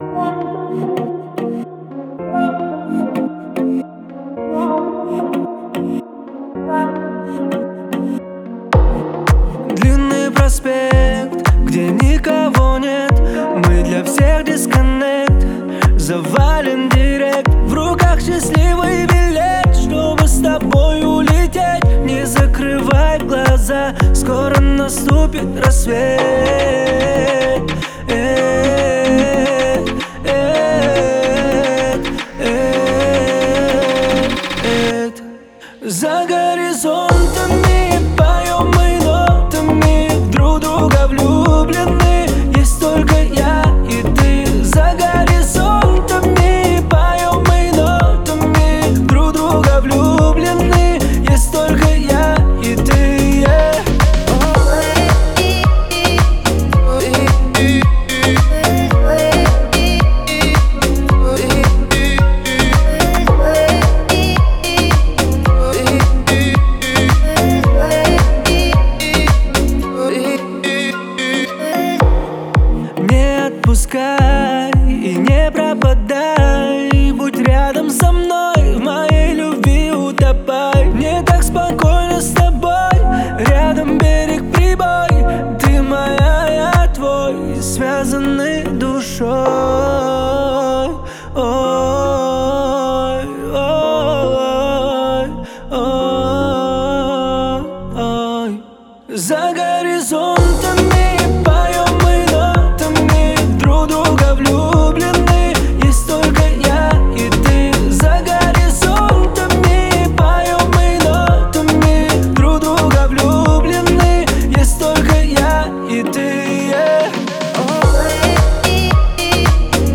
отличается мелодичностью и яркими синтезаторными партиями